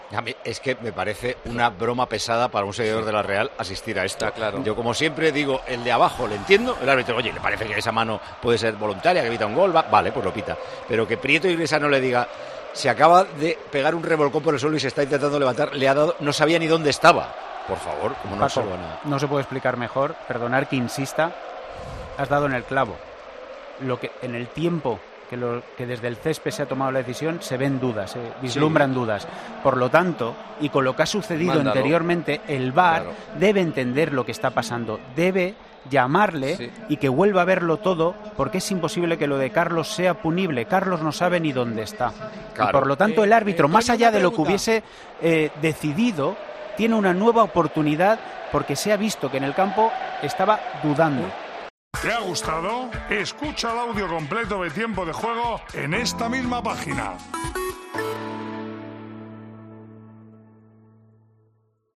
Al igual que Paco González durante la rentransmisión del partido entre Atlético y Real Sociedad. Escucha las palabras completas del directo de Tiempo de Juego.